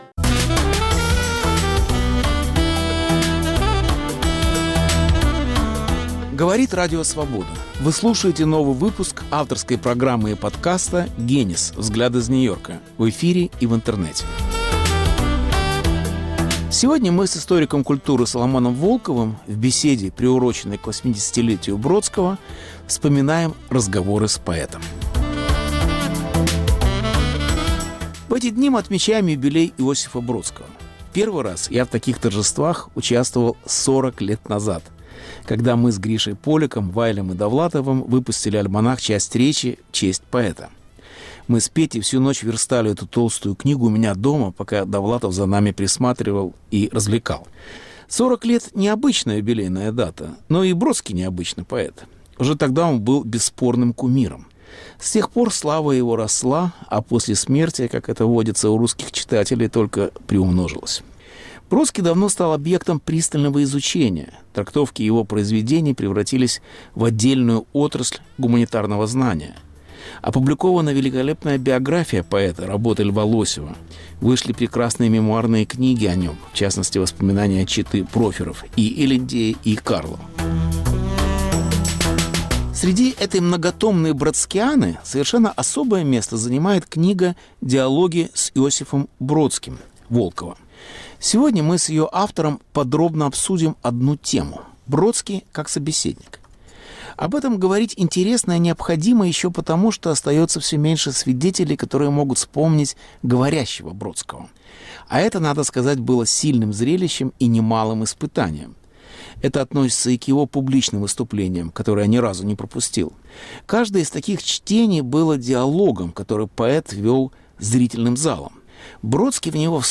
Бродский как собеседник Беседа с Соломоном Волковым, приуроченная к 80-летию поэта